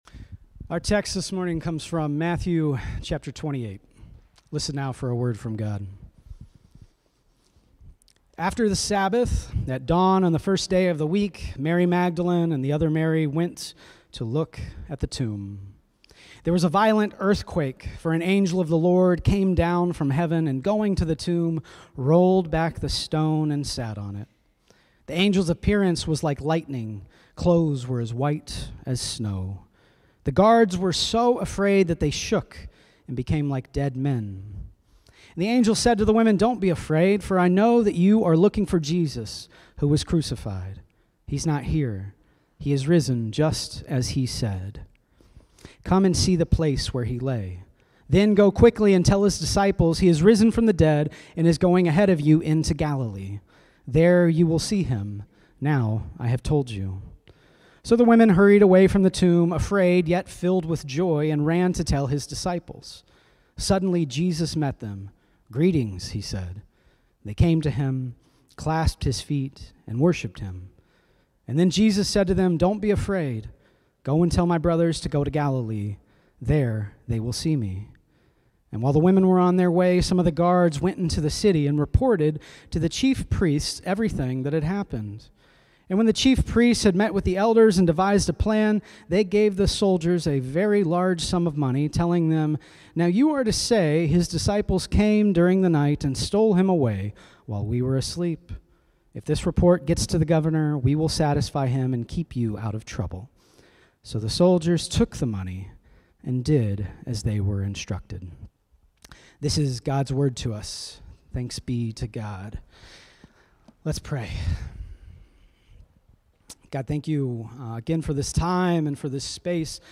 Sermons | Fort Street Presbyterian Church